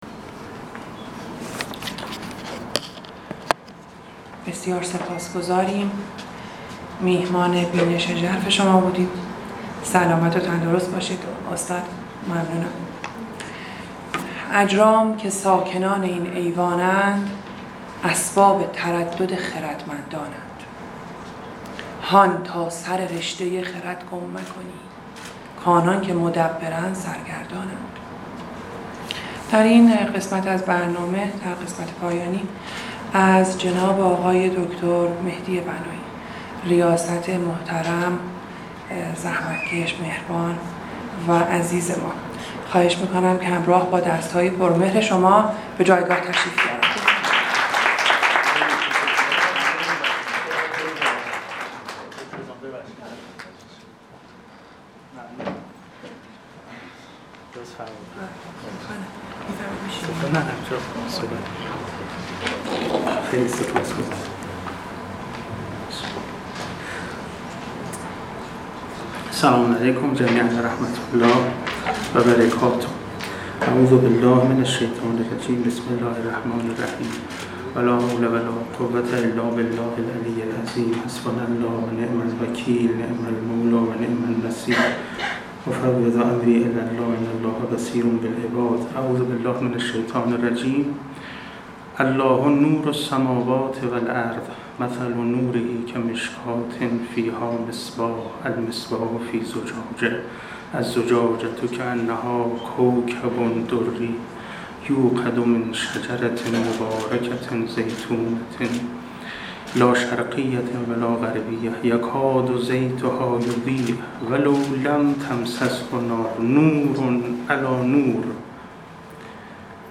مراسم افتتاحیه هفته پژوهش